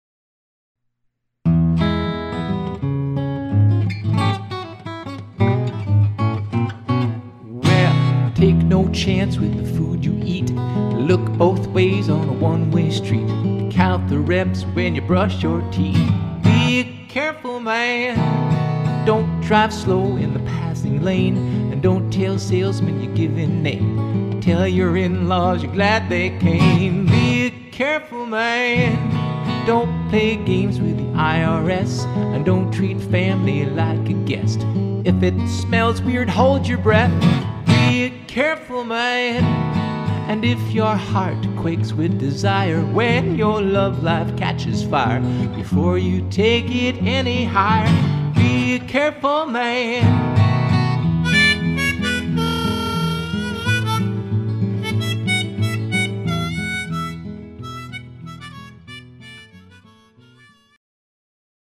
The all acoustic album
People like it best when I play my guitar and sing... live.